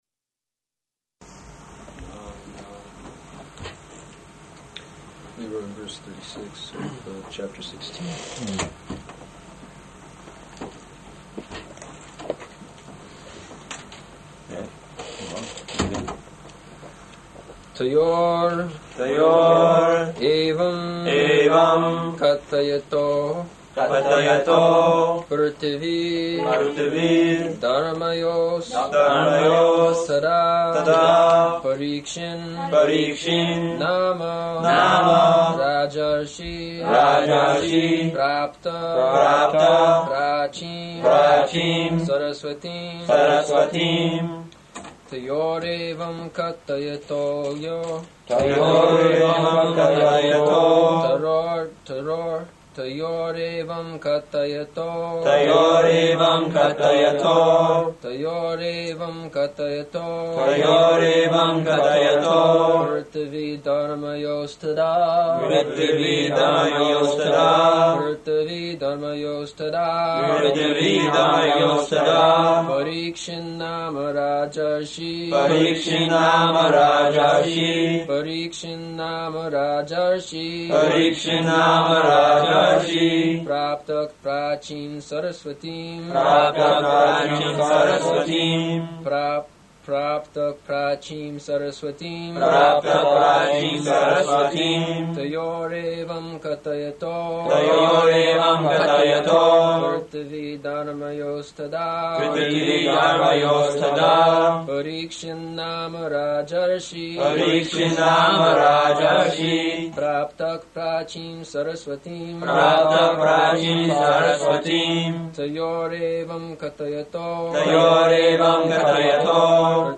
Śrīmad-Bhāgavatam 1.16.36 --:-- --:-- Type: Srimad-Bhagavatam Dated: January 30th 1974 Location: Tokyo Audio file: 740130SB.TOK.mp3 Prabhupāda: So there are others coming?